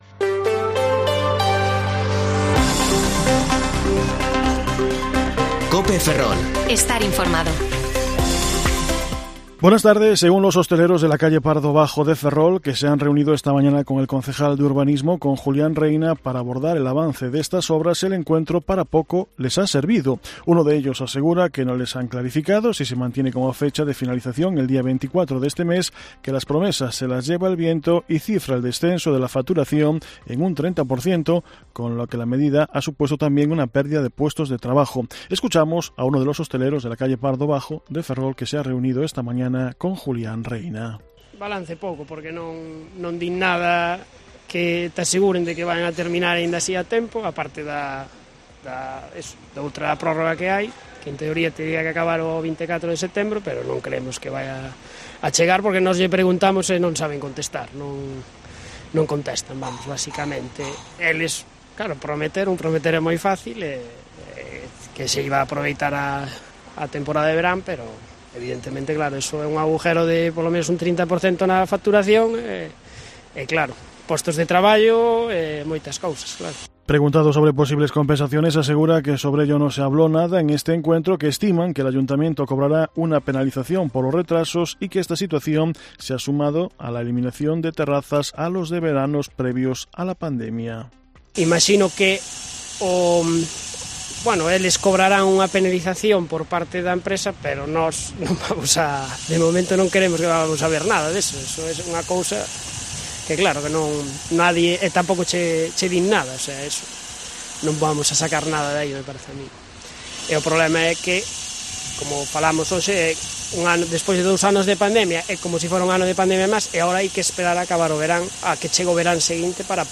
Informativo Mediodía COPE Ferrol 6/9/2022 (De 14,20 a 14,30 horas)